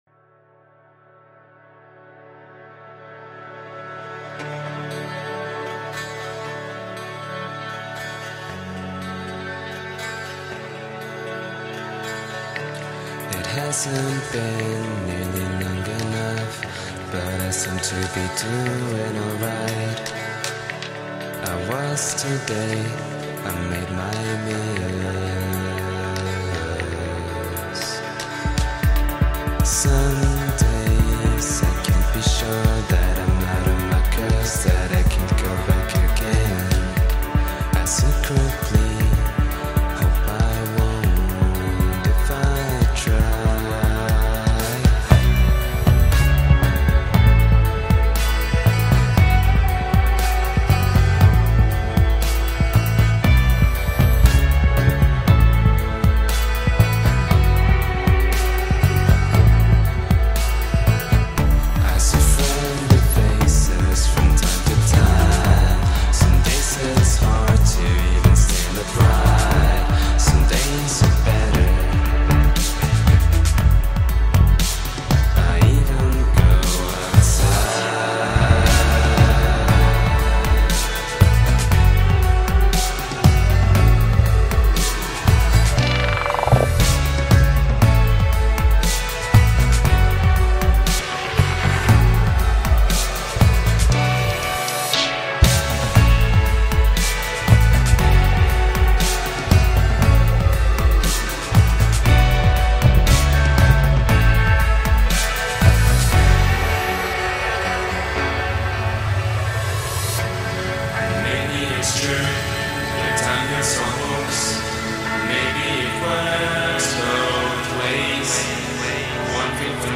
intense singing